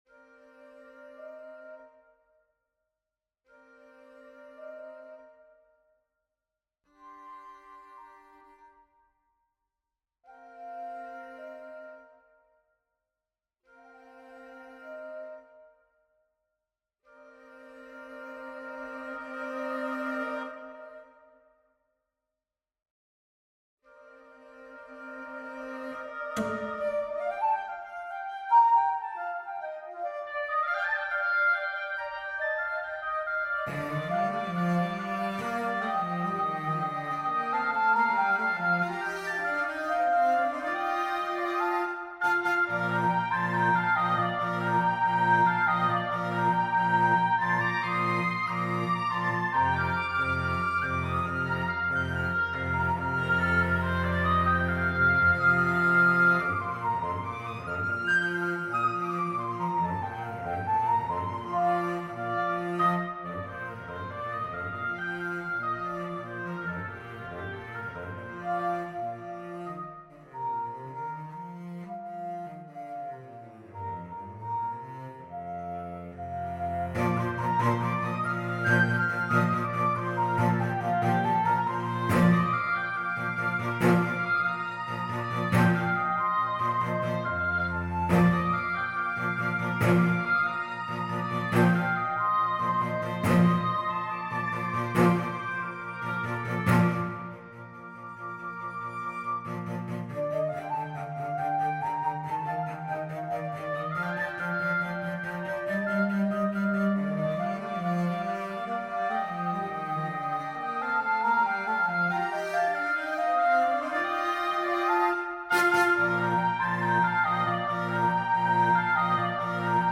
3 Leaves - 3 Movement work for Flute, Oboe, Cello